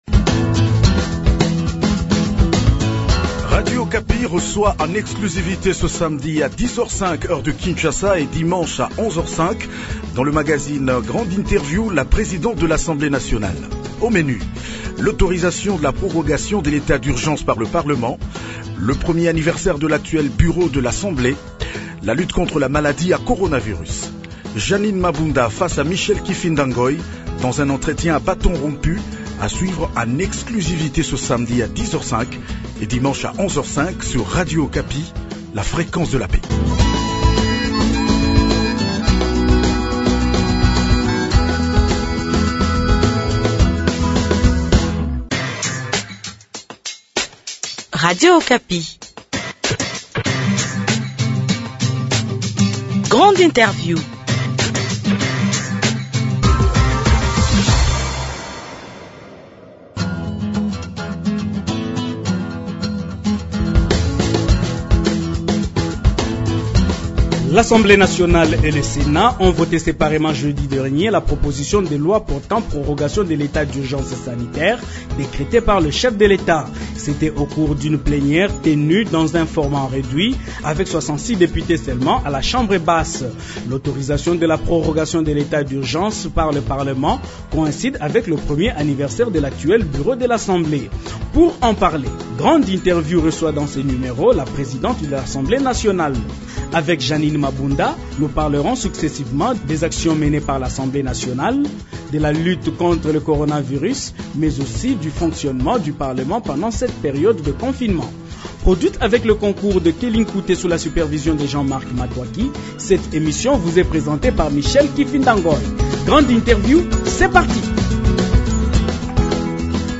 Pour en parler à Radio Okapi, Grande Interview reçoit la présidente de l’Assemblée nationale. Jeanine Mabunda a affirmé que les activités parlementaires classiques vont continuer ; contrôle et productions législatives dans le respect des mesures sanitaires du COVID-19.